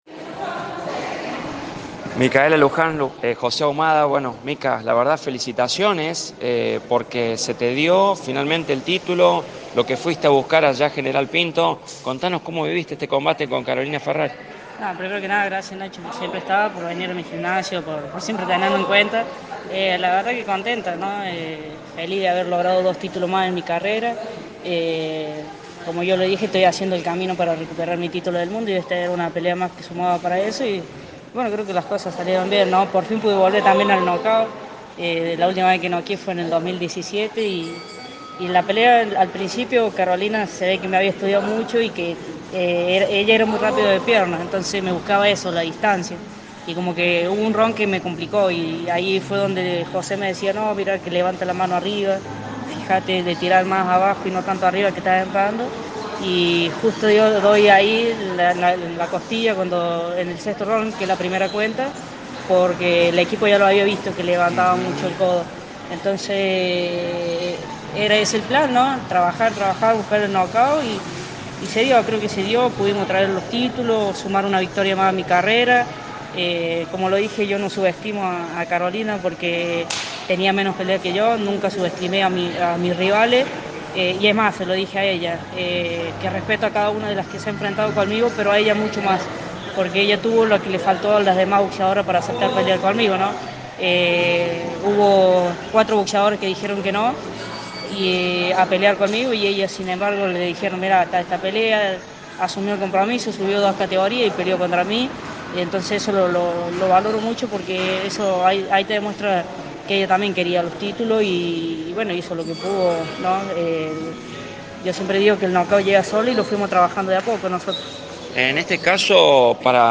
En diálogo con Show Deportivo en Radio La Bomba, la mercedino indicó: “Estoy feliz de haber ganado dos títulos más en mi carrera. E un paso más hacia la recuperación de mi título mundial”.